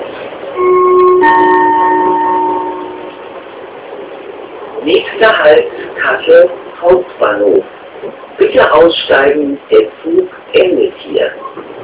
Zusätzlich ertönt eine Frauenstimme und kündigt den nächsten Halt an.
KS_Hauptbahnhof_Ansage.wav